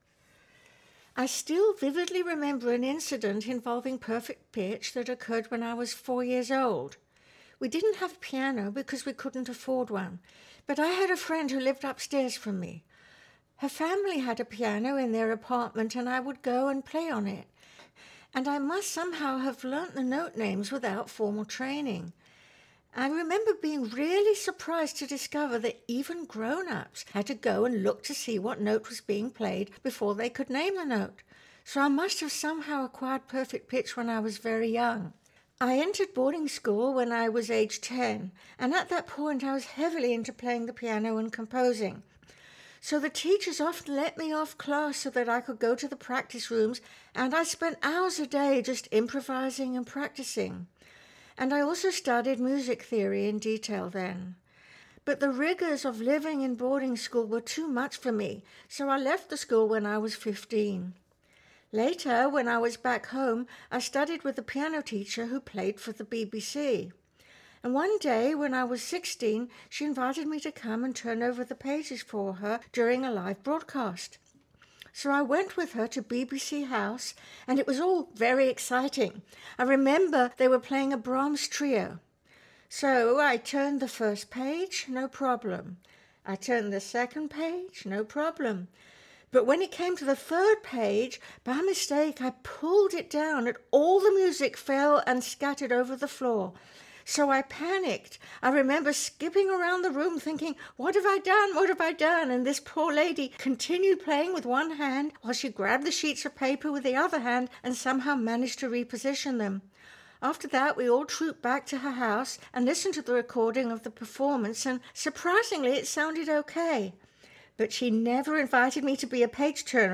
In the following recollection she describes being drawn to piano music from a neighboring apartment.